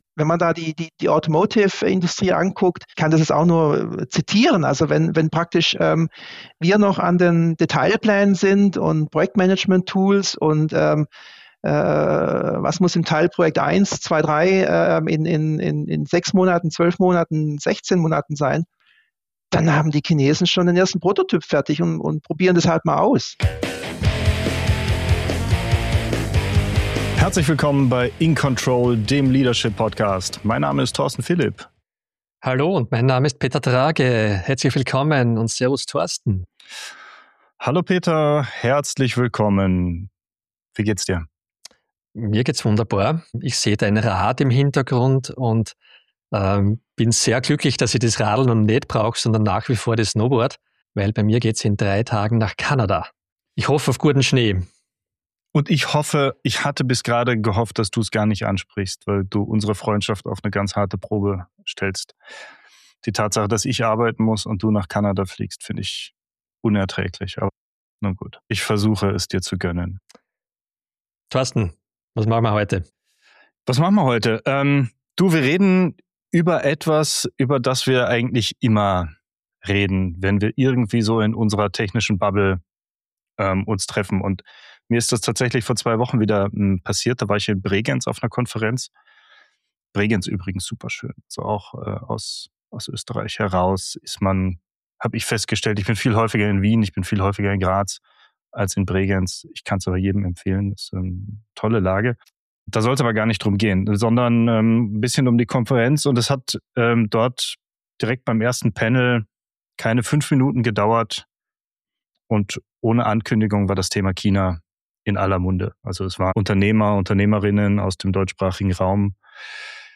Ein offenes und differenziertes Gespräch über Leadership, Kultur, Geschwindigkeit und die Frage, was wir voneinander lernen können.